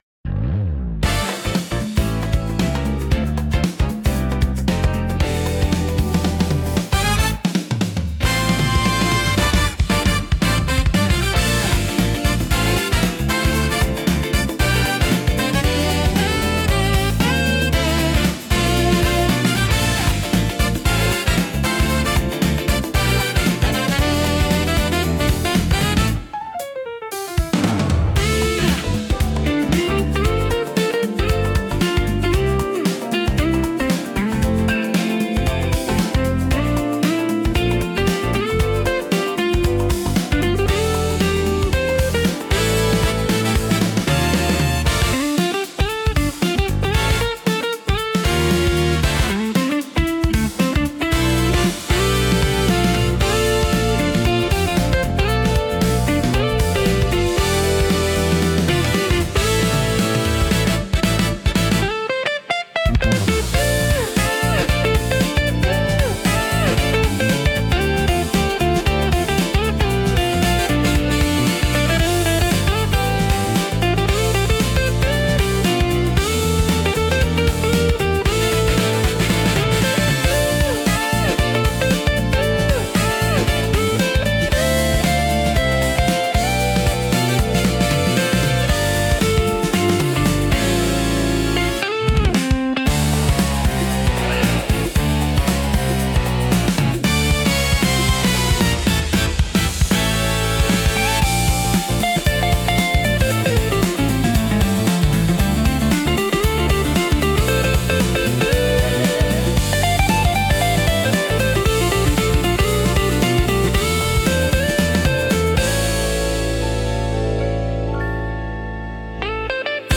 軽快で洗練されたメロディとリズムが、都会的でスタイリッシュな雰囲気を演出します。
BGMセミオーダーシステム オリジナルのおしゃれは、サックスを主体としたジャズフュージョン風の爽やかな曲調が特徴です。
聴く人に洗練された印象を与えつつ、リラックスと活気のバランスを巧みに表現します。